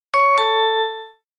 Door_Bell.ogg